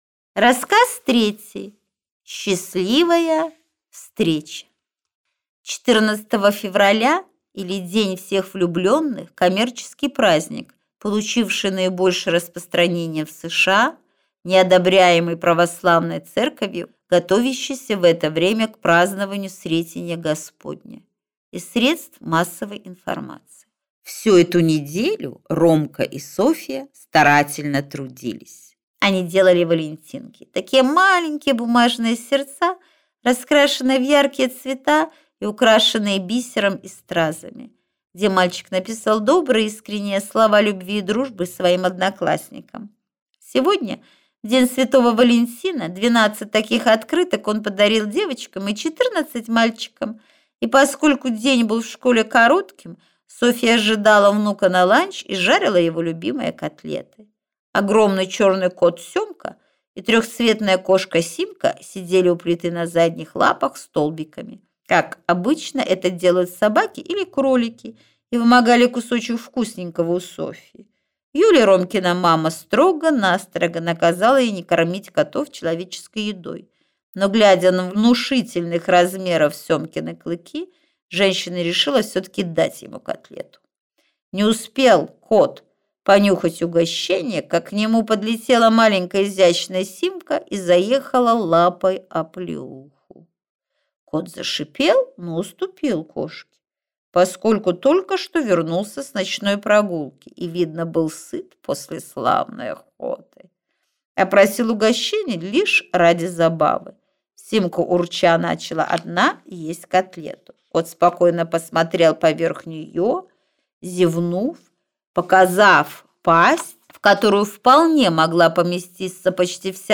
Рассказы о Ромке и его бабушке - Аудиокнига